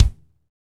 Index of /90_sSampleCDs/Northstar - Drumscapes Roland/KIK_Kicks/KIK_A_C Kicks x
KIK A C K0GL.wav